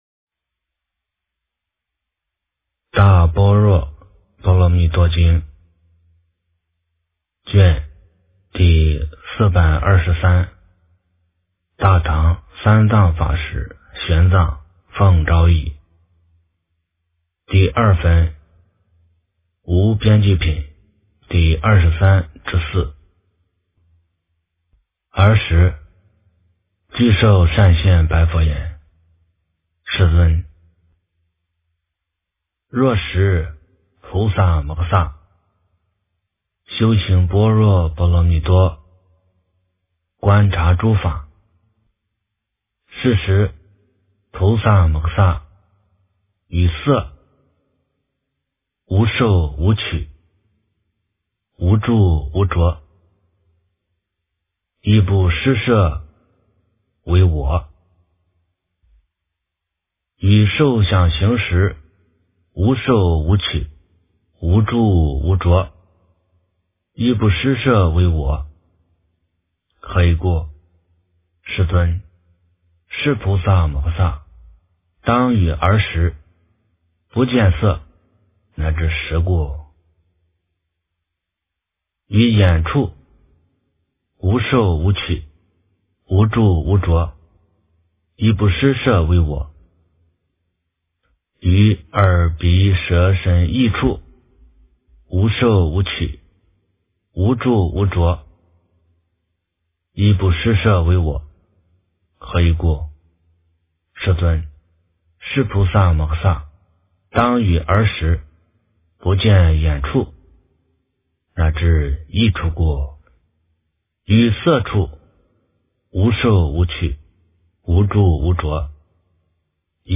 大般若波罗蜜多经第423卷 - 诵经 - 云佛论坛